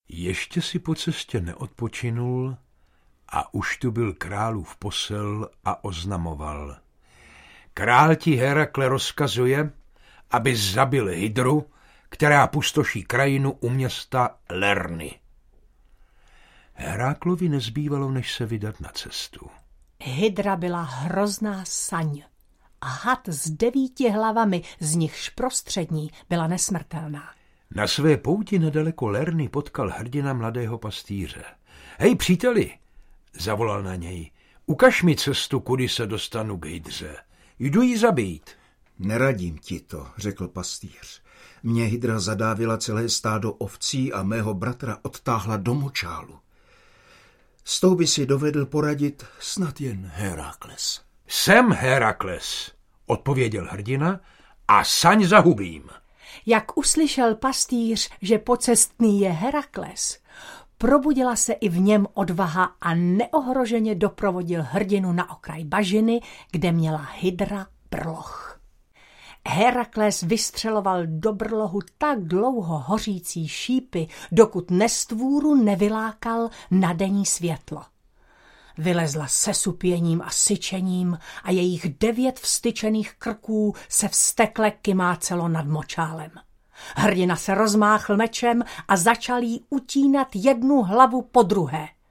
Audio kniha
• InterpretTaťjana Medvecká, Petr Pelzer, František Němec